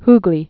(hglē)